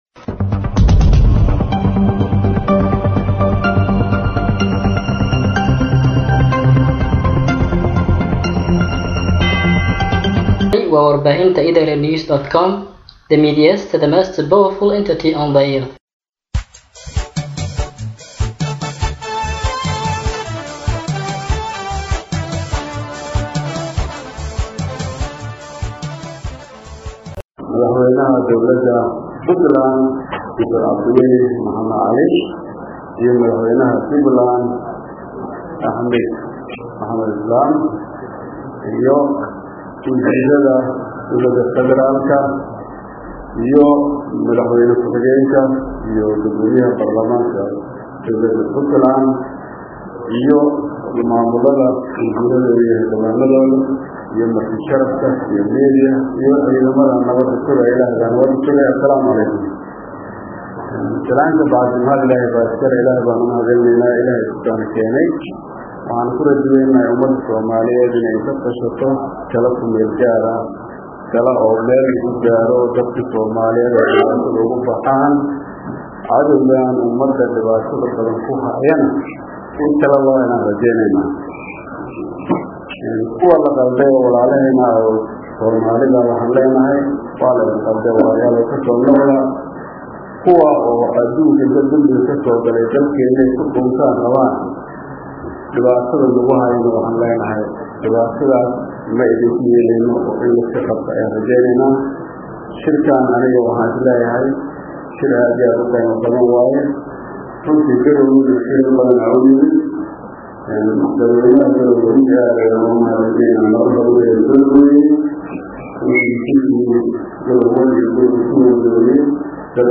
Dhageyso: Khudbadii Madaxweyne Shariif Xasan Ka Jeediyey Shirkii Hiigsiga Soomaaliya Ee Garowe